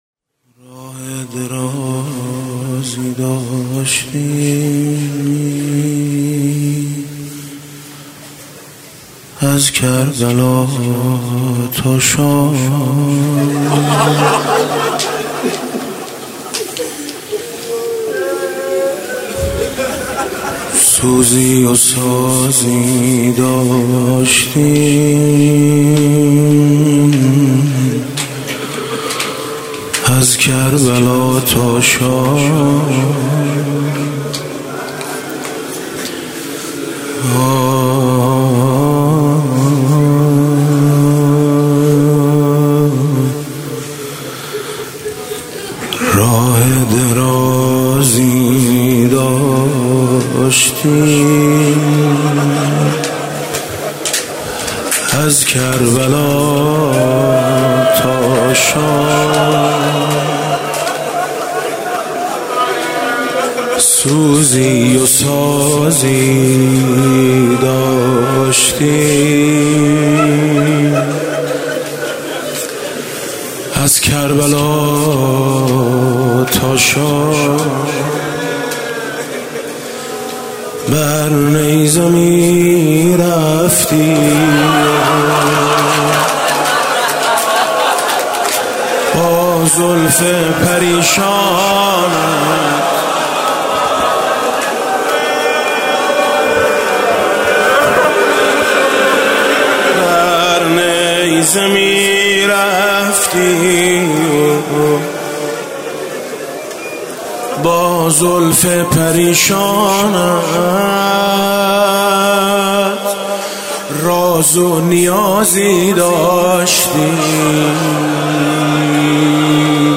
شب سوم محرم 97 - هیئت میثاق با شهدا - روضه - راه درازی داشتیم از کربلا تا شام
روضه حاج میثم مطیعی محرم 97